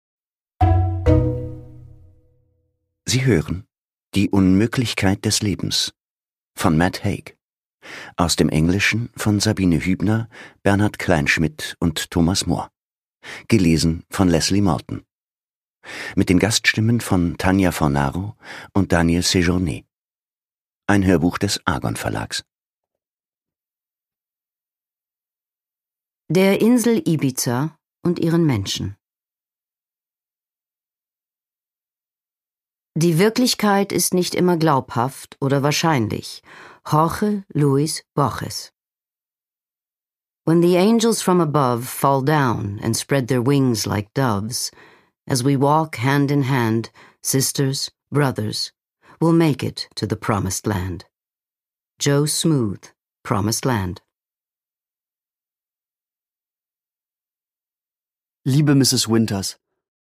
Produkttyp: Hörbuch-Download
Gelesen von: Leslie Malton